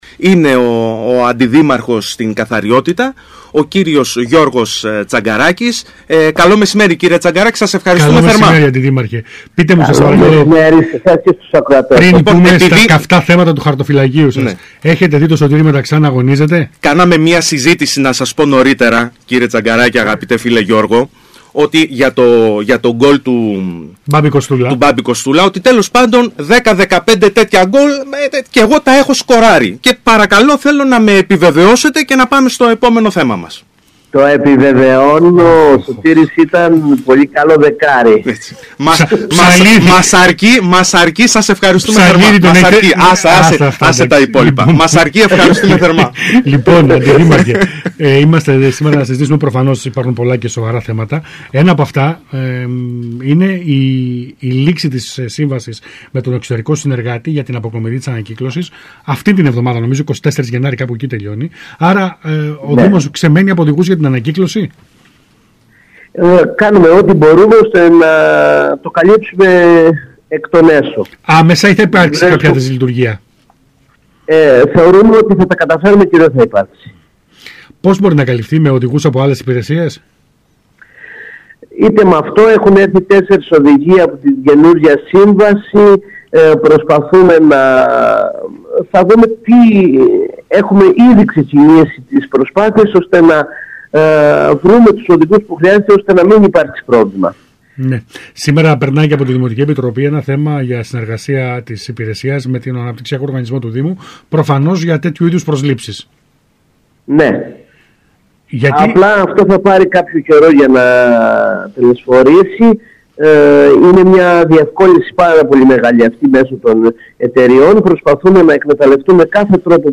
Ο Αντιδήμαρχος Καθαριότητας Γιώργος Τσαγκαράκης μίλησε για το θέμα στον ΣΚΑΙ Κρήτης 92.1